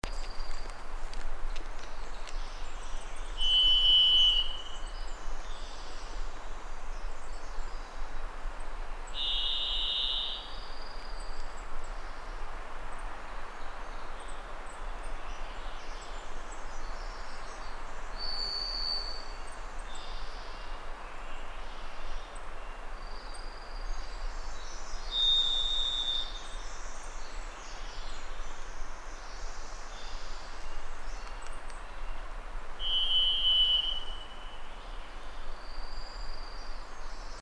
Varied Thrush Song.mp3